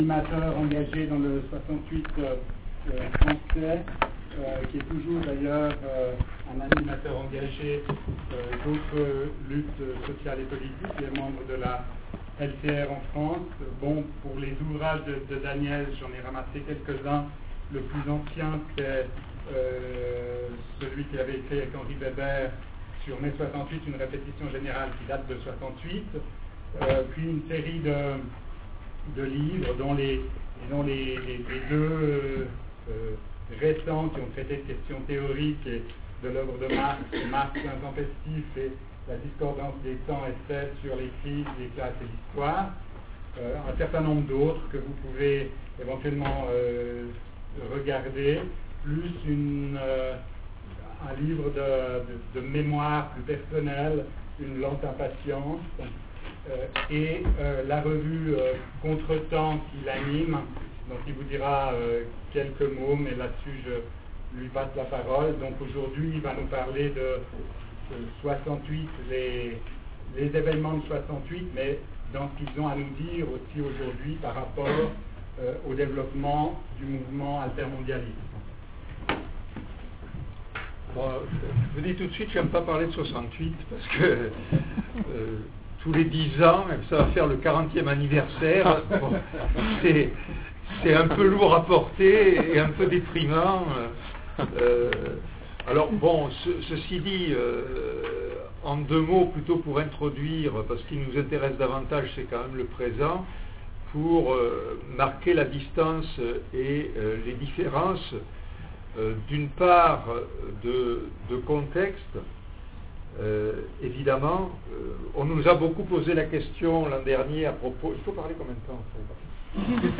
Conférence à l'UNIL - La Brèche numérique